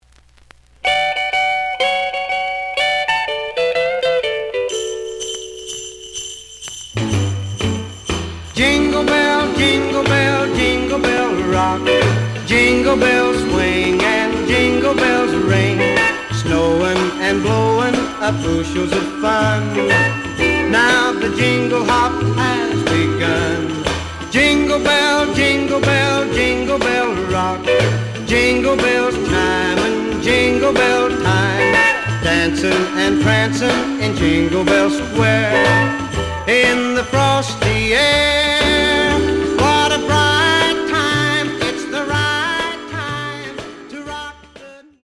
The audio sample is recorded from the actual item.
●Genre: Rhythm And Blues / Rock 'n' Roll
A side plays good.